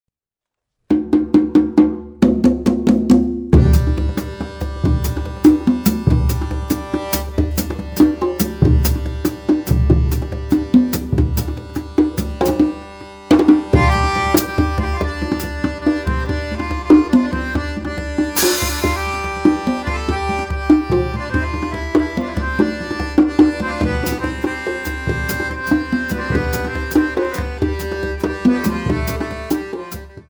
multi hand drum set
accordion